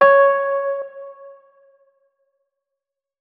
electric_piano